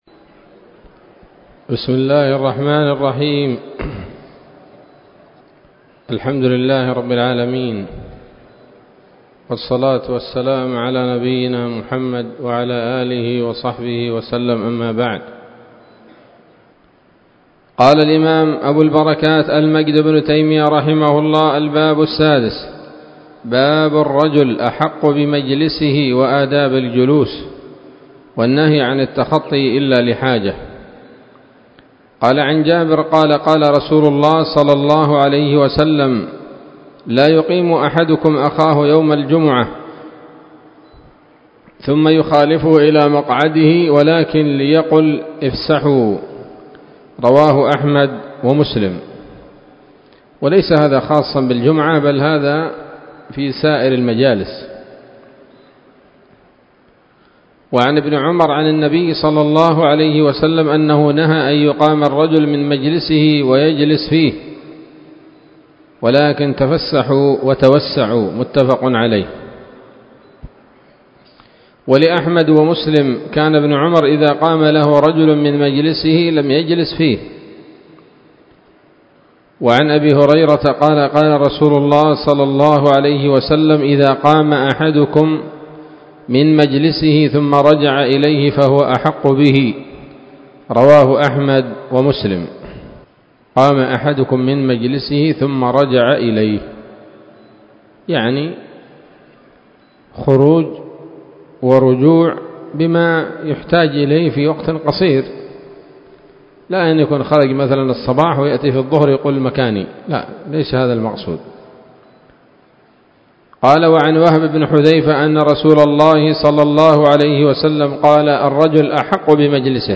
الدرس السادس عشر من ‌‌‌‌أَبْوَاب الجمعة من نيل الأوطار